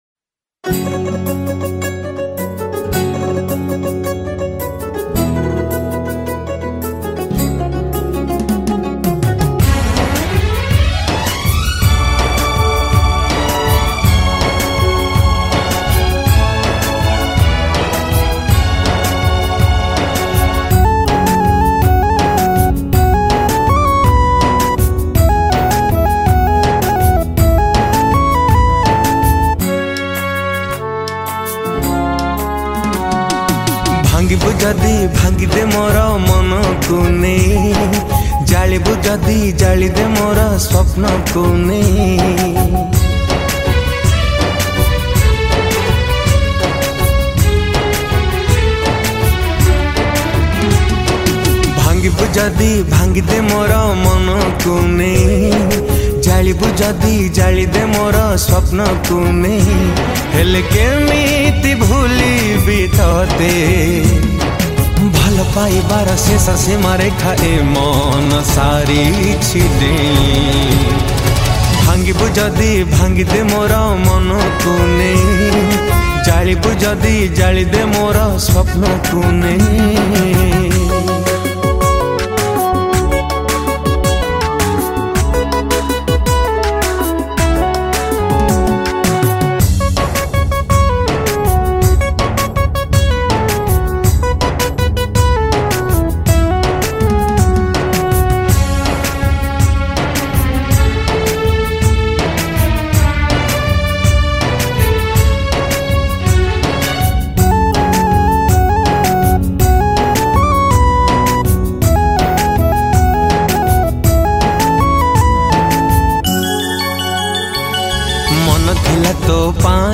Odia Sad Romantic Songs